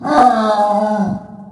zombie_take_damage_2.ogg